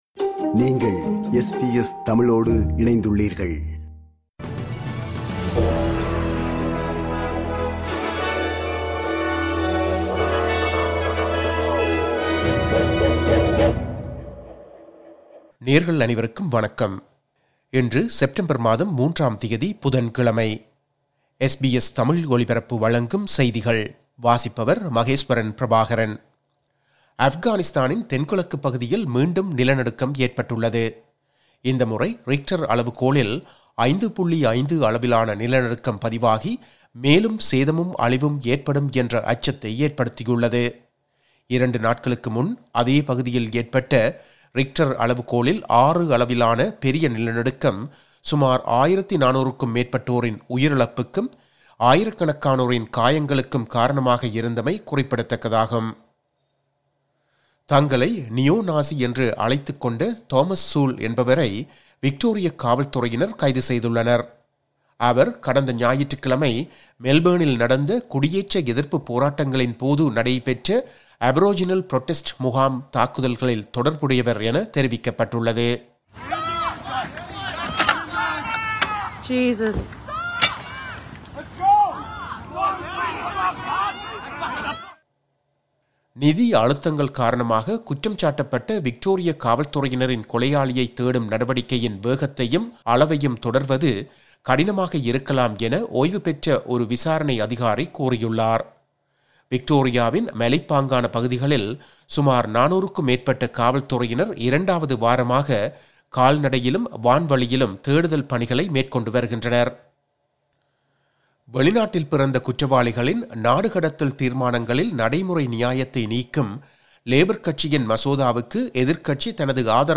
SBS தமிழ் ஒலிபரப்பின் இன்றைய (புதன்கிழமை 03/09/2025) செய்திகள்.